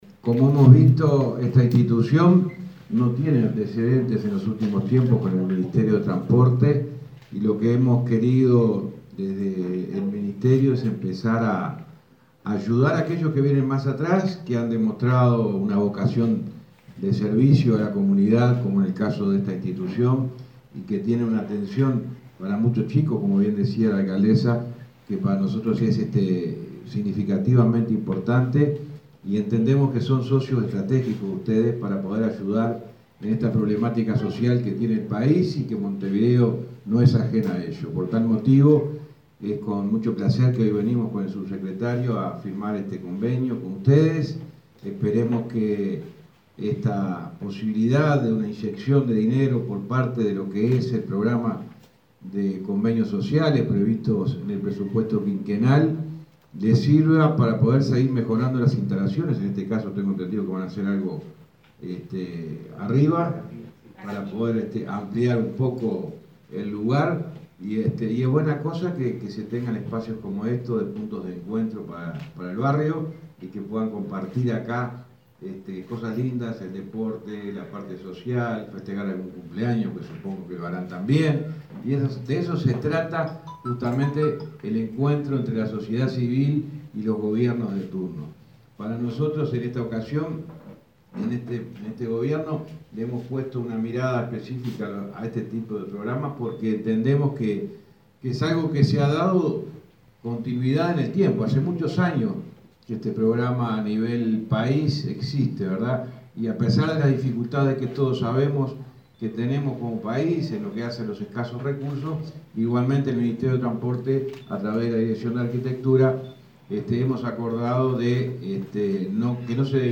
Palabras del ministro de Transporte, José Luis Falero